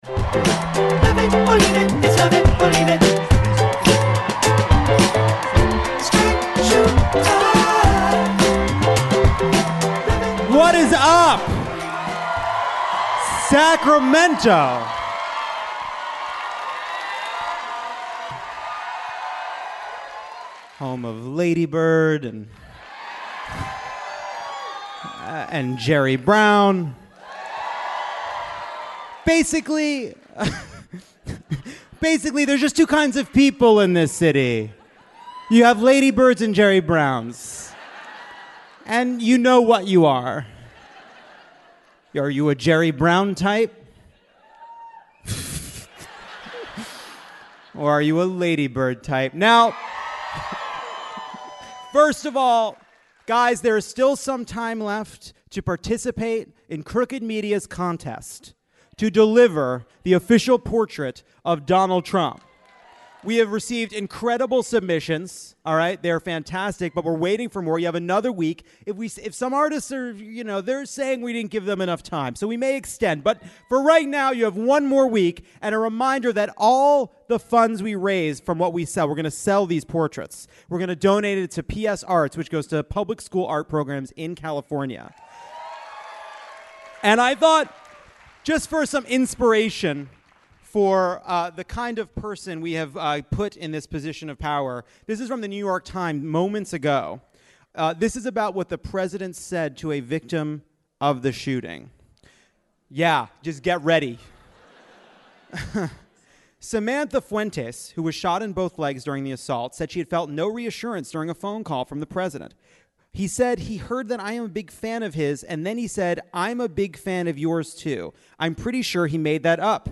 Mayor Michael Tubbs of Stockton, Black Lives Matter activist Alicia Garza, and comedian Jenny Yang join Jon in Sacramento to break down the week’s news and mock Marco Rubio because he deserves it.